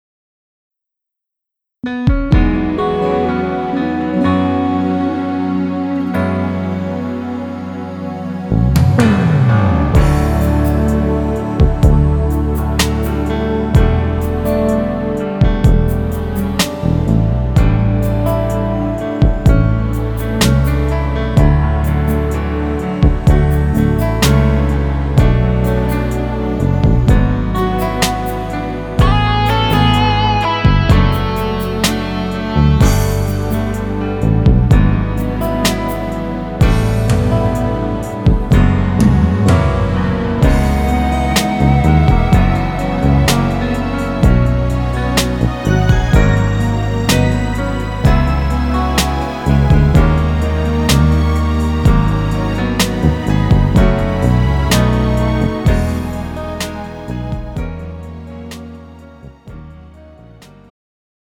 음정 -1키
장르 축가 구분 Pro MR